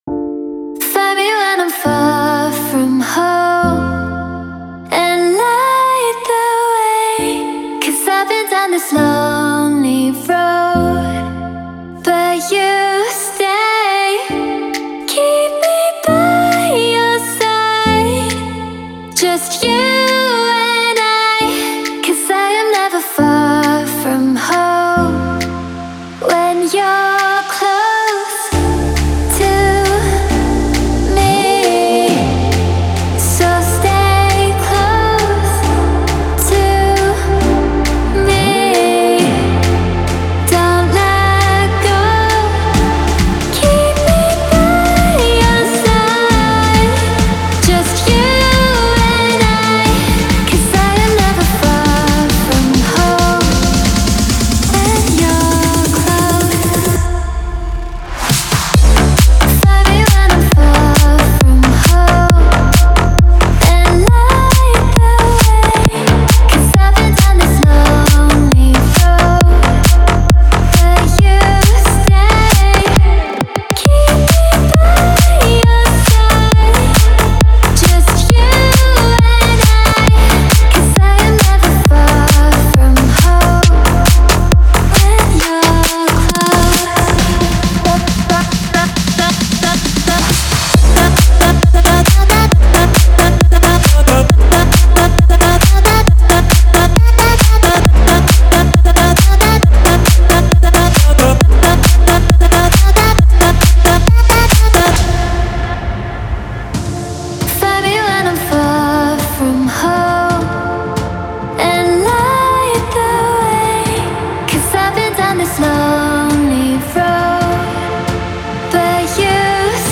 это трек в жанре EDM